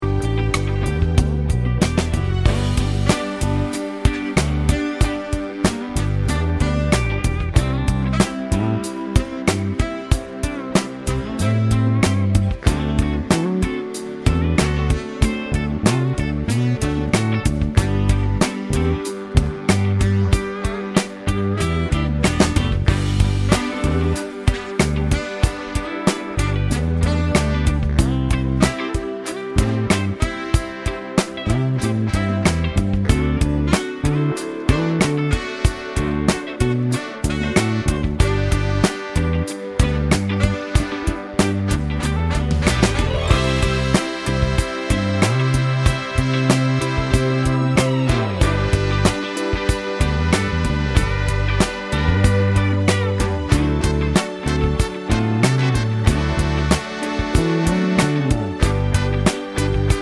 no Backing Vocals Soul / Motown 4:10 Buy £1.50